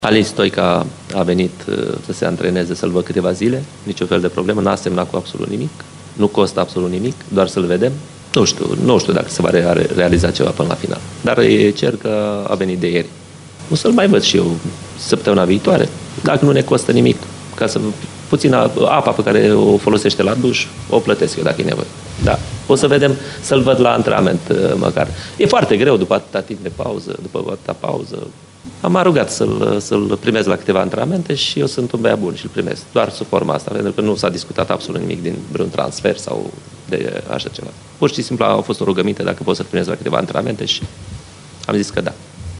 Una din noutăţile perioadei, la formaţia de pe Bega e prezenţa lui Alin Stoica. Antrenorul Petre Grigoraş a lămurit situaţia mijlocaşului ce a mai avoluat la Poli în urmă cu câţiva ani: